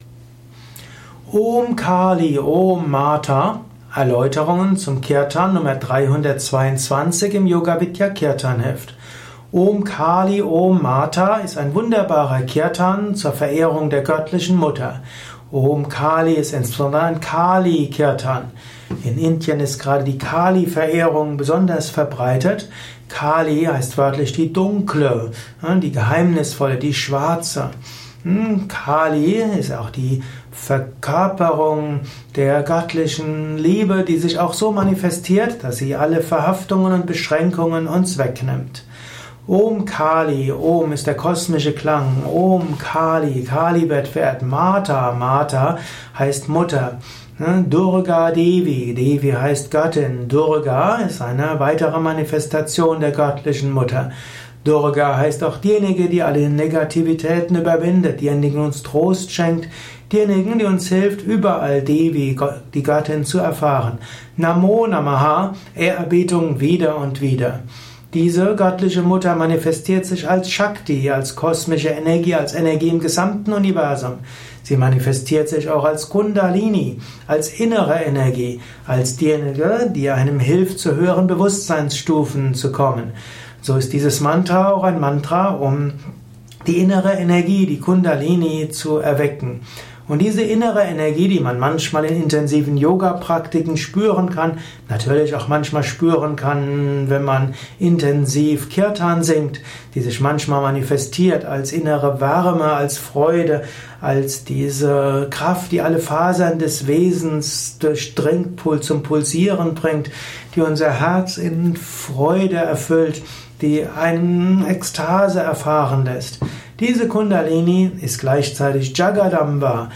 unter der Nr. 322 im Yoga Vidya Kirtanheft , Tonspur eines
Kirtan Lehrvideos. Erfahre mehr über die Bedeutung des Om Kali Om